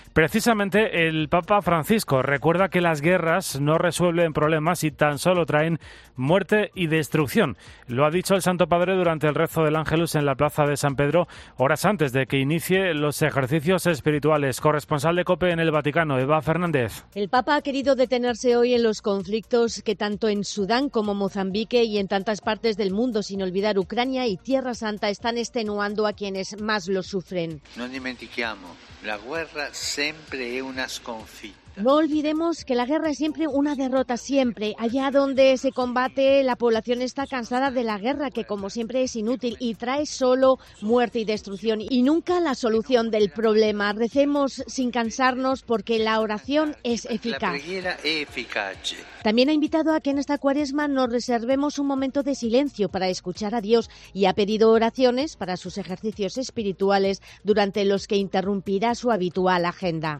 El Papa Francisco presidió el Ángelus en el primer domingo de Cuaresma
El pontífice recordó que "han pasado ya 10 meses del comienzo del conflicto armado en Sudán que ha provocado una gravísima situación humanitaria", ante los miles de fieles reunidos en la plaza de San Pedro.